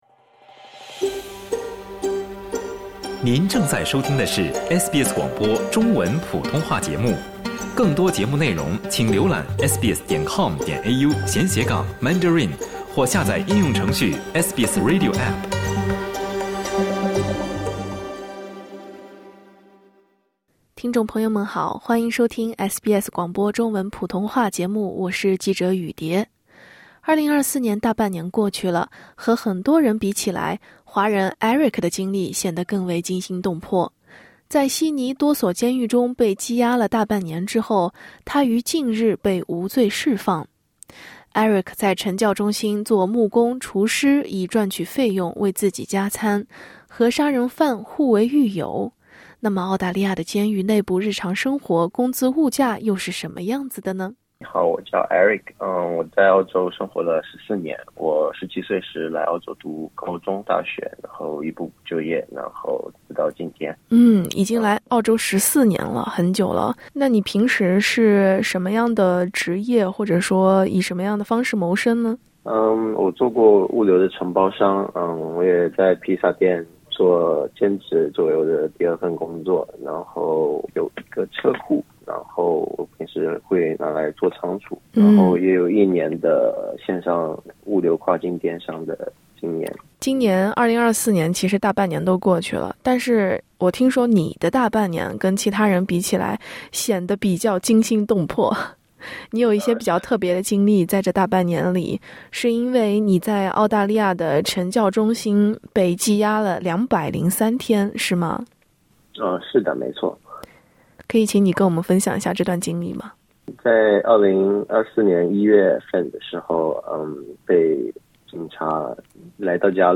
华人电商无罪获释后口述澳洲狱中经历：“我左右两边睡的都是杀人犯”